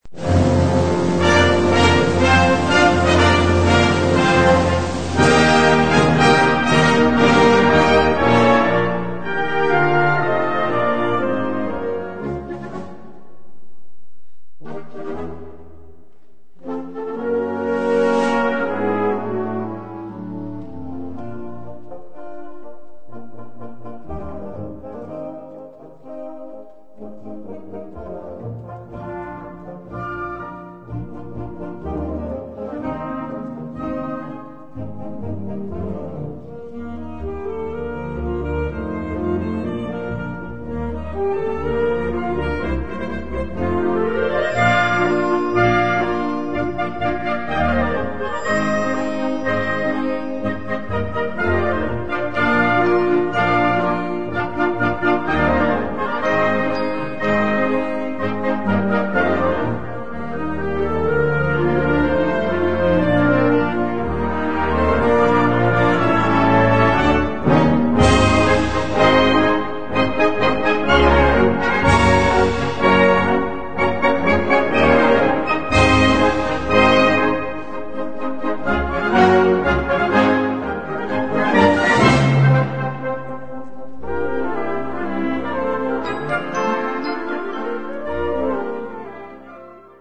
Categorie Harmonie/Fanfare/Brass-orkest
Subcategorie Hedendaagse blaasmuziek (1945-heden)
Bezetting Ha (harmonieorkest)
1 moderato
2 andantino
3 allegro